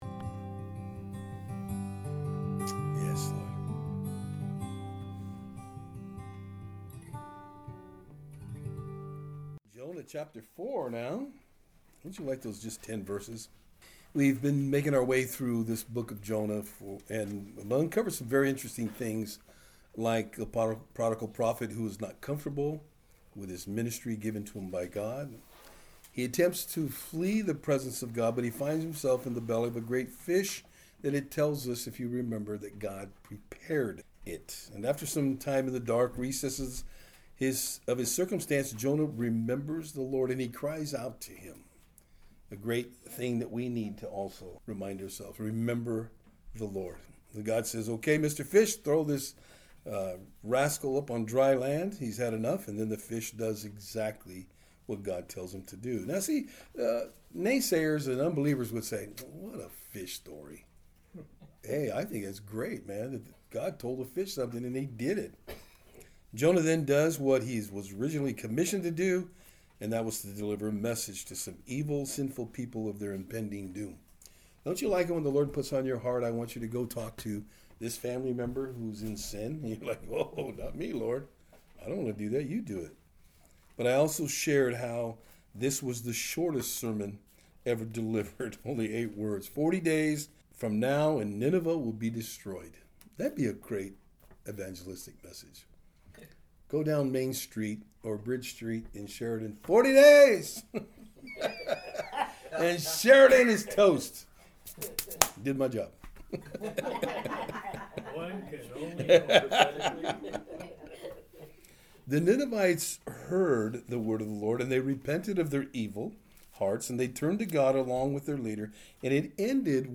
Service Type: Thursday Afternoon Topics: Fretting , rebellious , Running From God « “Forty Days & POW” It’s All God’s Grace »